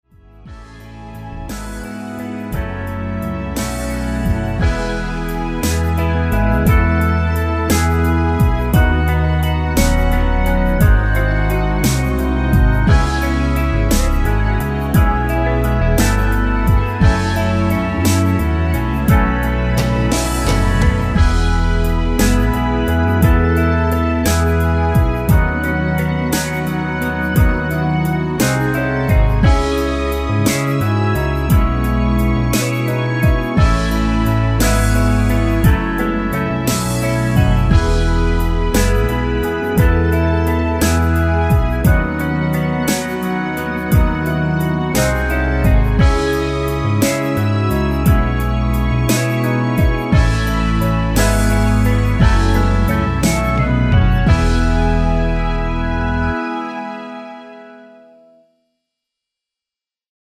엔딩이 페이드 아웃이라 엔딩을 만들어 놓았습니다.
(미리듣기는 끝에서 1분입니다.)
Eb
앞부분30초, 뒷부분30초씩 편집해서 올려 드리고 있습니다.
중간에 음이 끈어지고 다시 나오는 이유는